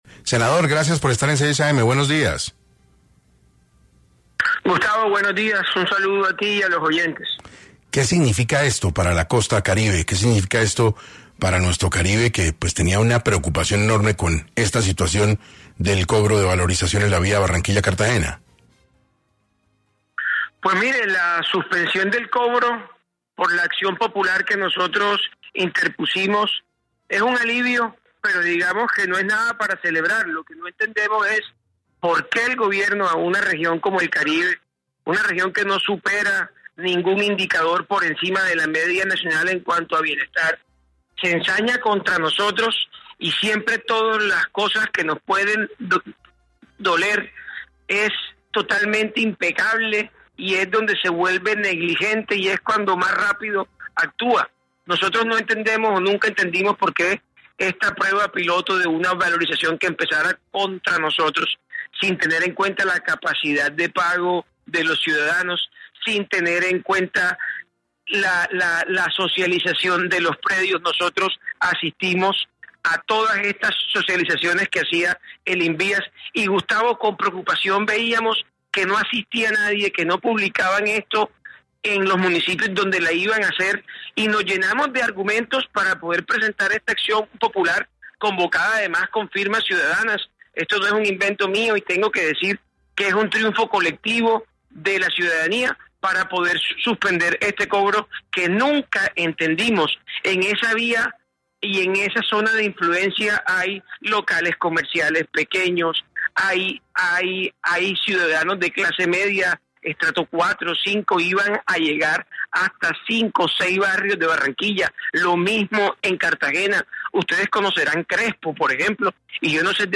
Carlos Meisel, senador, habló en 6AM acerca de los cobros que se pensaban recaudar para la valorización de la Vía al Mar entre Barranquilla y Cartagena y las afectaciones que traería.